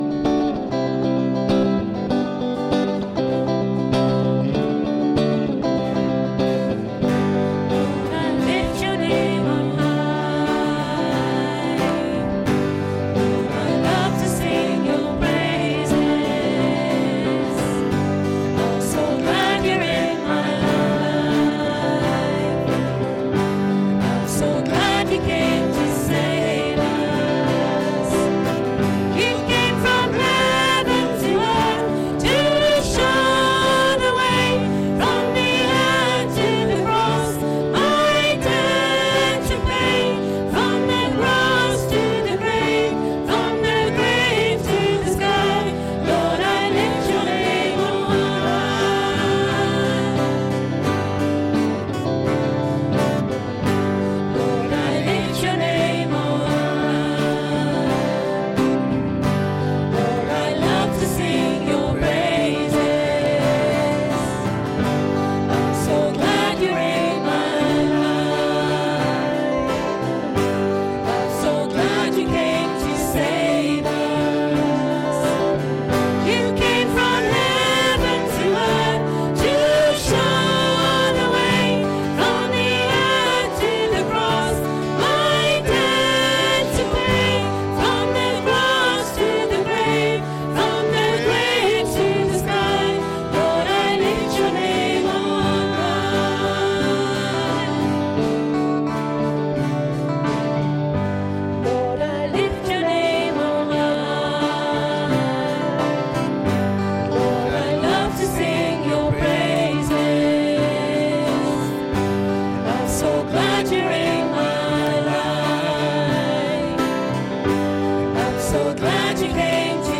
Service Audio